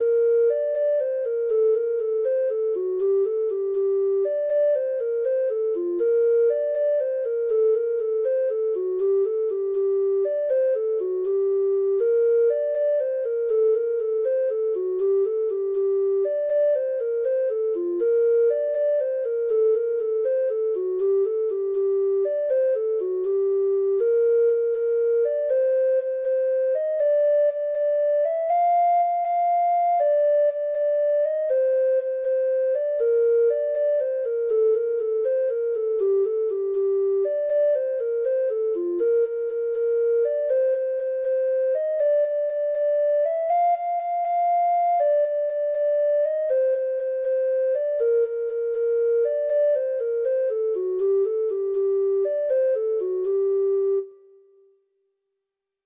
Traditional Trad. The King Of The Rath (Irish Folk Song) (Ireland) Treble Clef Instrument version
Traditional Music of unknown author.
Irish